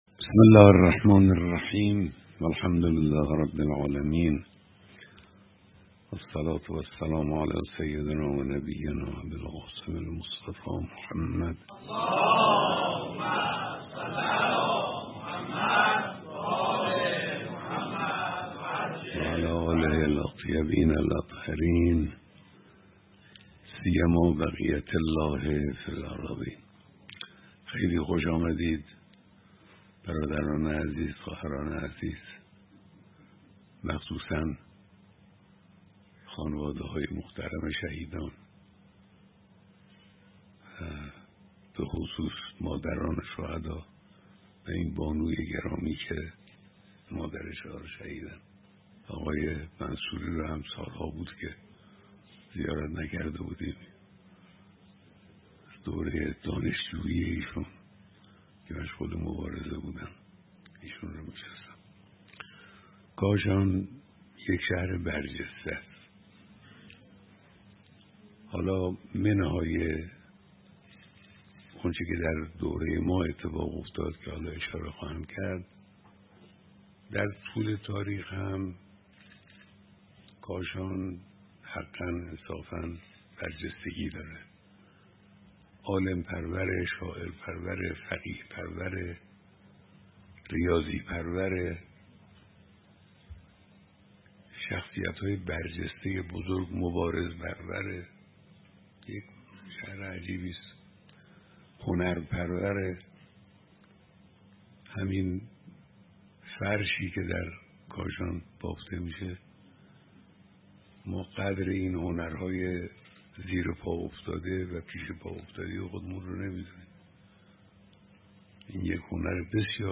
بیانات رهبر معظم انقلاب اسلامی در دیدار دست‌اندرکاران برگزاری کنگره ملّی بزرگداشت یک هزار و هشتصد و هشتاد شهید کاشان که در تاریخ ۲۶ دی ۱۴۰۳ برگزار شده بود، امشب در محل برگزاری همایش در کاشان منتشر شد.